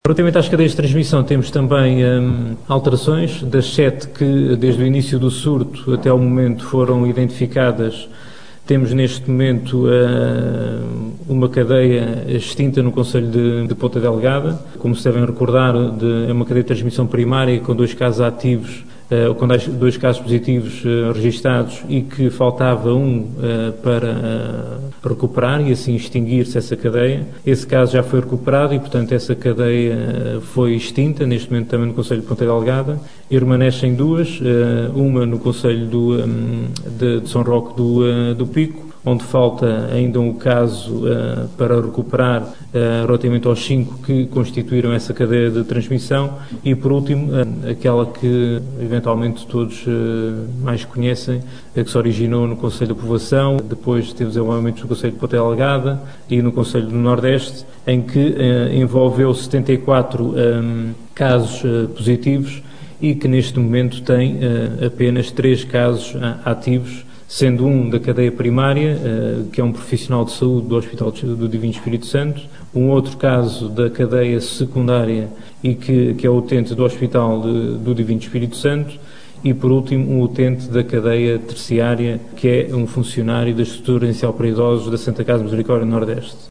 O anúncio foi feito, esta terça-feira, pelo responsável da Autoridade de Saúde Regional, em conferência de imprensa, em Angra do Heroísmo, que recordou que desde o inicio do surto foram identificadas sete cadeias.